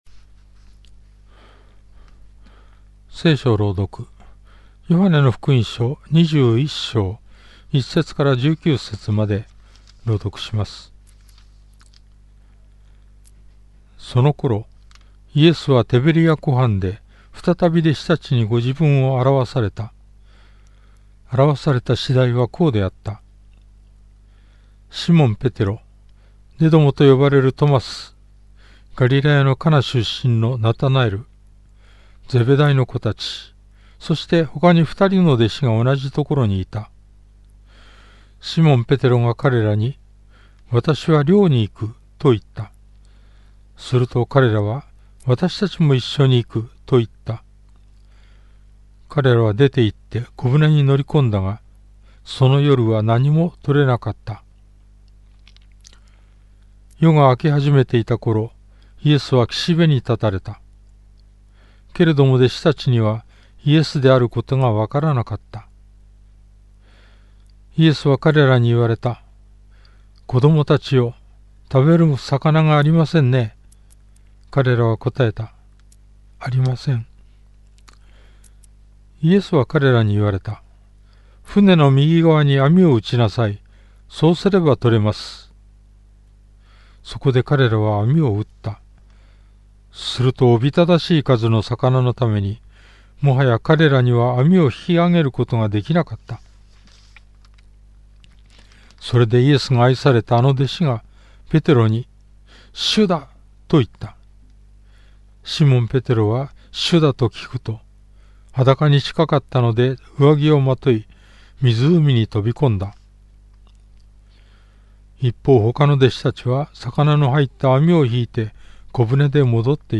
BibleReading_J21.1-19.mp3